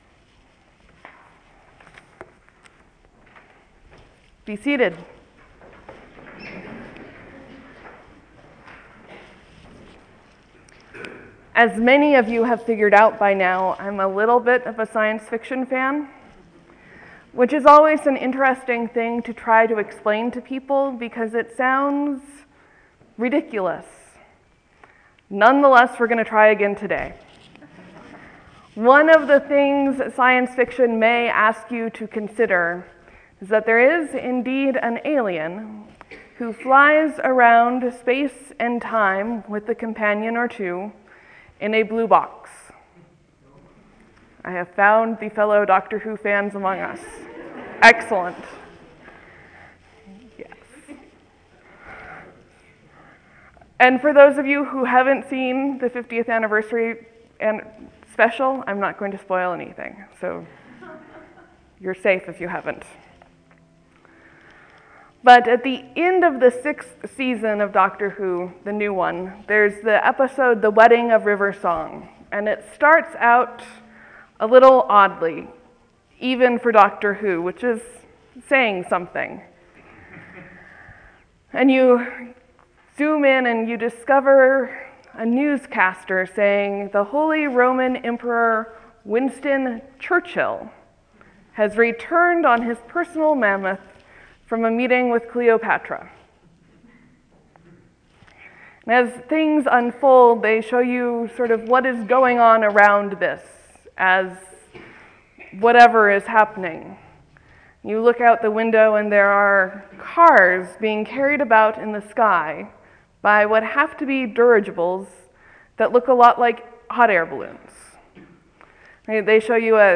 Sermon, , , , 1 Comment